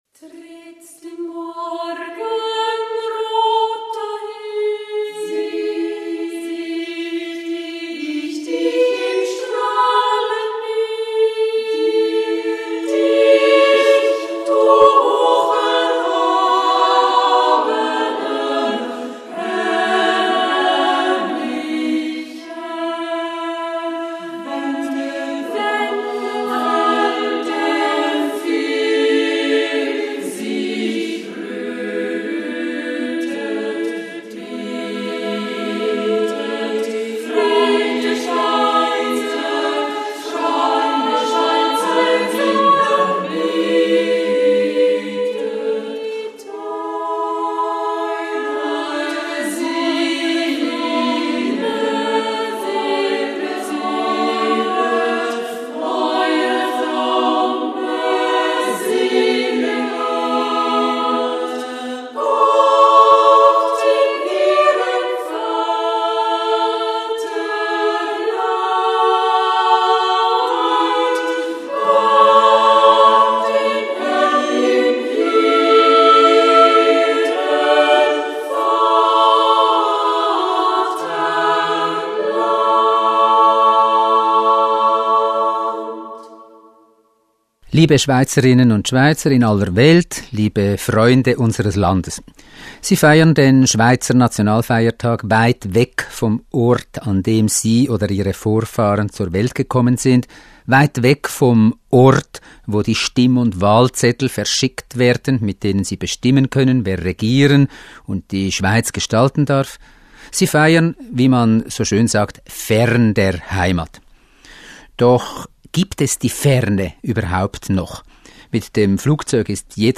Ansprache von Bundespräsident Moritz Leuenberger an die Auslandschweizerinnen und Auslandschweizer zum Schweizer Nationalfeiertag